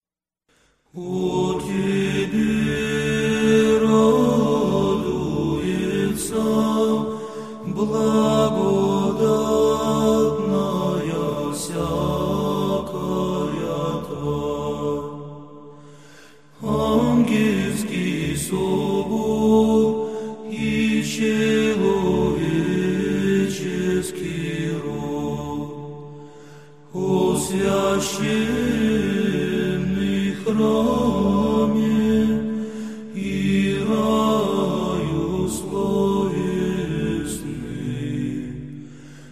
this recording of the same melody by the monks of Valaam to see how beautifully and spiritually it can be chanted.
Znammeny_chant_excerpt.mp3